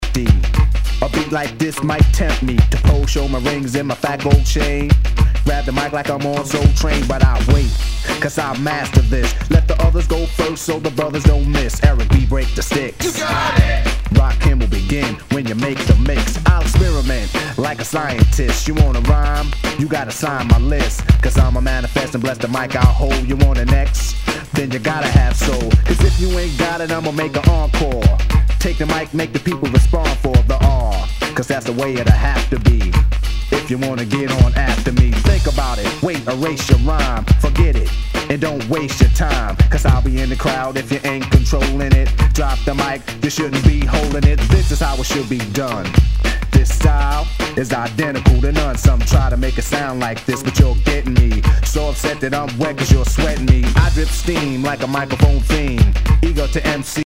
HIPHOP/R&B
[VG ] 平均的中古盤。スレ、キズ少々あり（ストレスに感じない程度のノイズが入ることも有り）